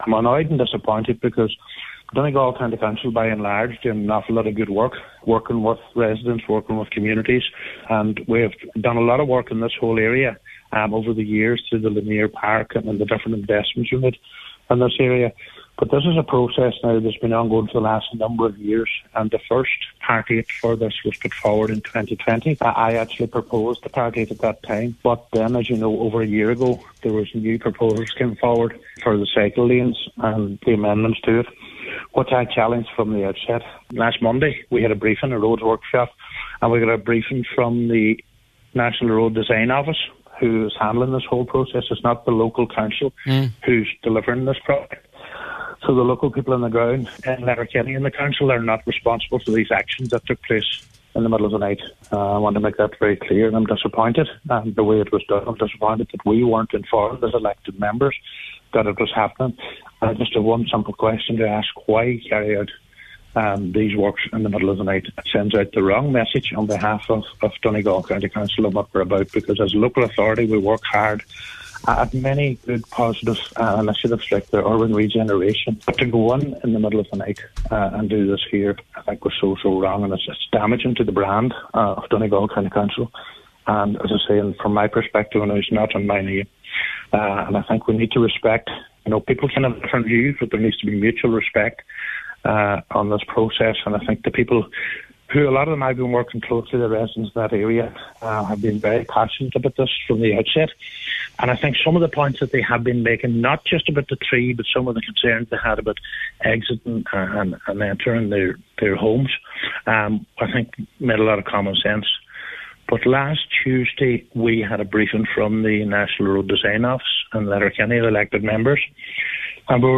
On today’s Nine til Noon Show, he said this will damage the council’s reputation…….